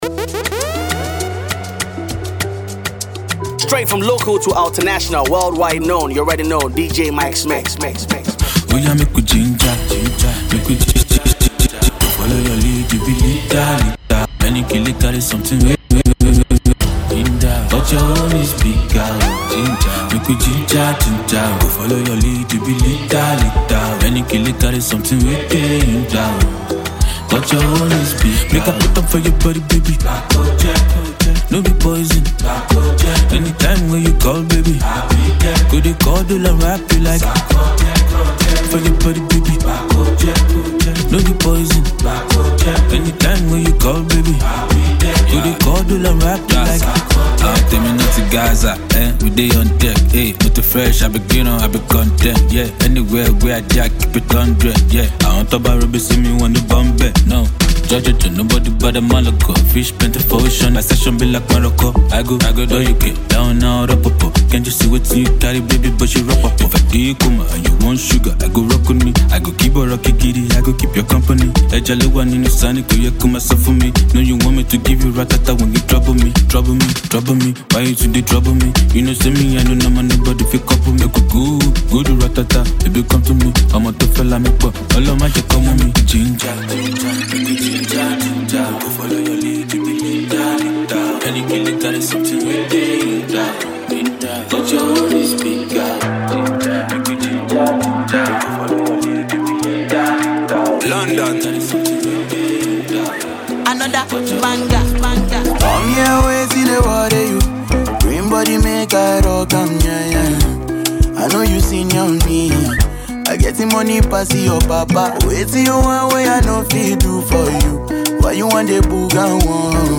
the best afrobeats songs in the mix